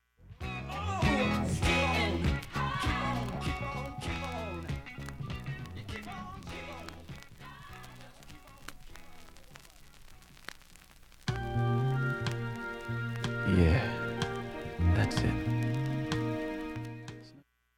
切手で言えばレア 下記も明記するものではありません チリも無く音質良好全曲試聴済み。
シカゴ・ソウル・シーンの ミュージシャンが多数参加